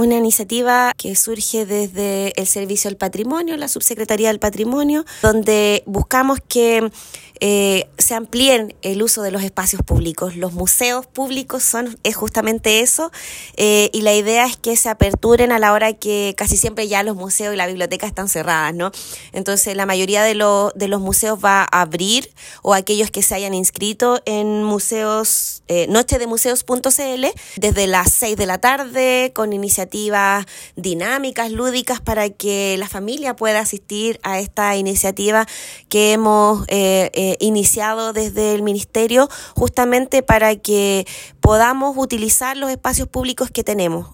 Esta iniciativa nace desde la Subsecretaría del Patrimonio Cultural, y busca que los espacios públicos administrados por DIBAM o aquellos que se hayan inscrito, puedan operar durante horas distintas a las habituales, según explicó la Seremi de las Culturas, las Artes y el Patrimonio, Cristina Añasco.